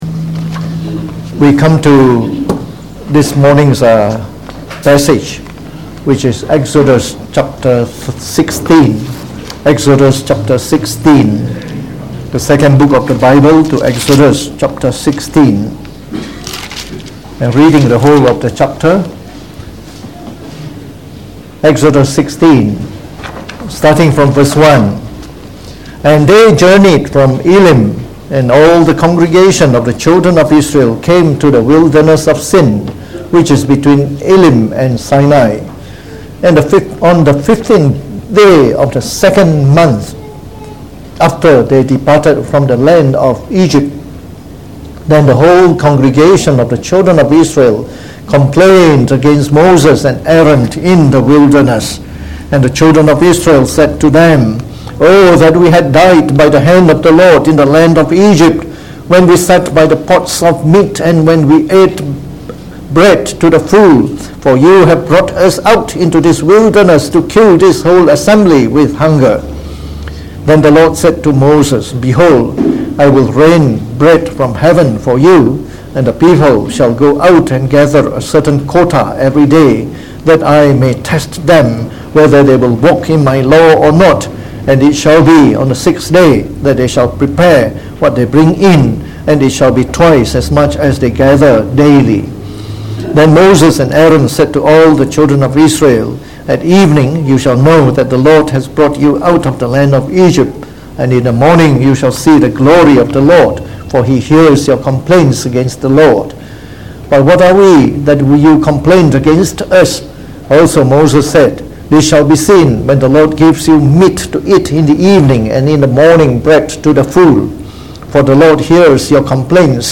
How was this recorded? delivered in the Morning Service